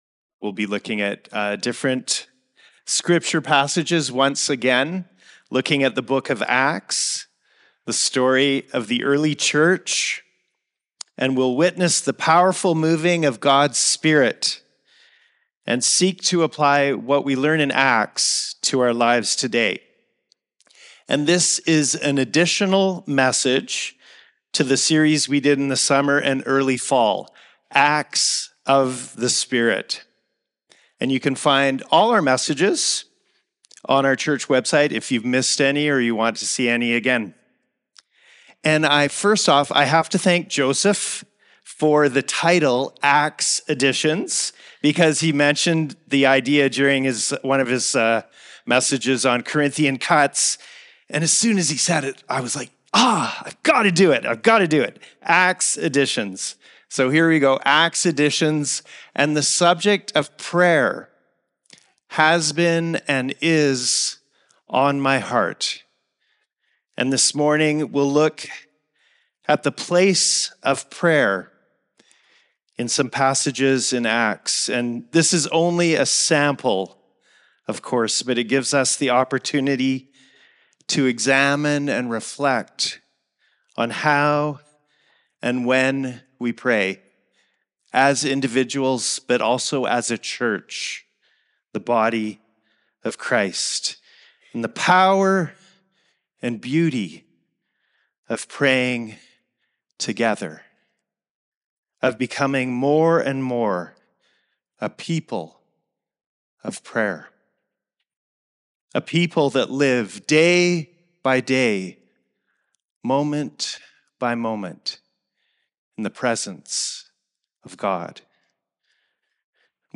Current Message